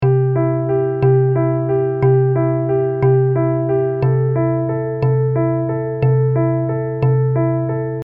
Im folgenden Beispiel sind Achtel-Triolen zu sehen, bei denen ein Schlag in drei gleich lange Achtelnoten aufgeteilt wird.
Achten Sie darauf, wie drei gleich lange Noten gleichmäßig einen Schlag ausfüllen.
Audiobeispiel_Achtelnoten_als_Triolen.mp3